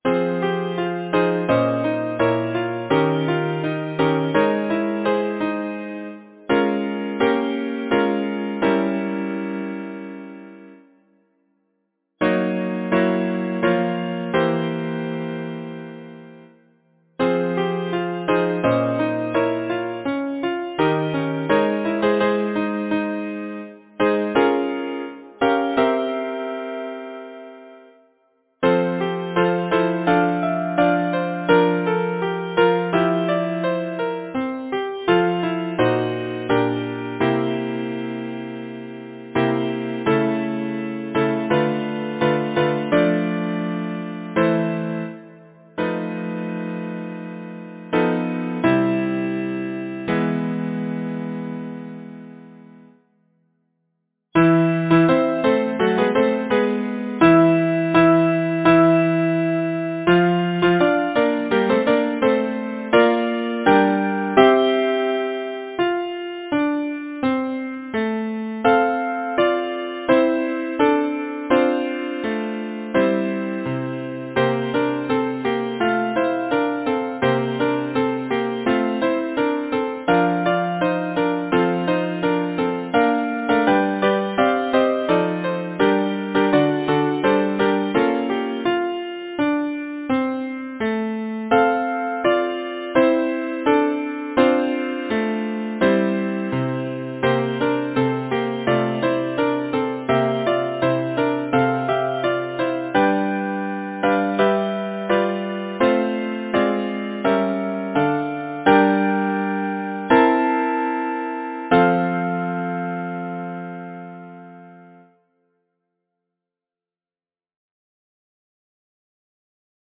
Title: Love Composer: Henry Kimball Hadley Lyricist: William Shakespeare Number of voices: 4vv Voicing: SATB Genre: Secular, Partsong
Language: English Instruments: A cappella